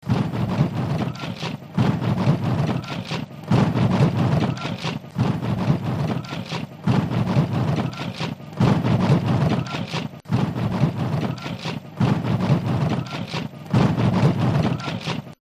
SONIDO TAMBORES TURBAS DE CUENCA